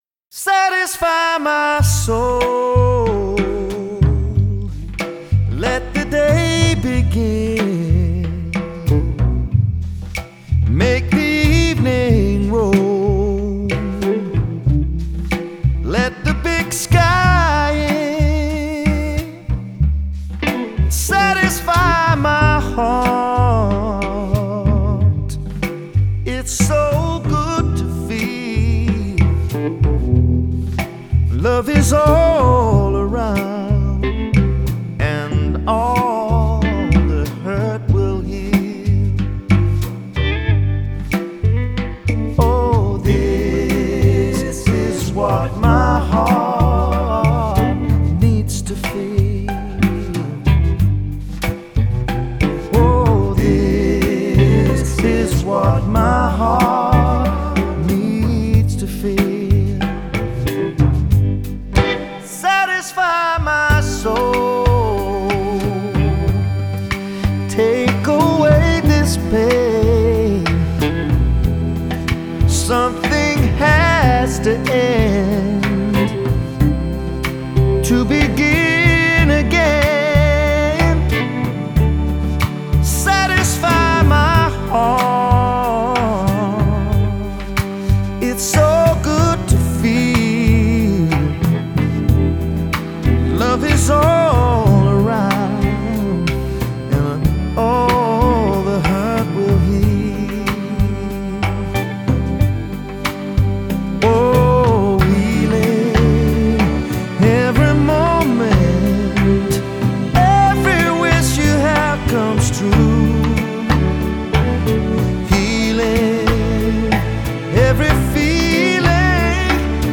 Reminds me of sixties soul music.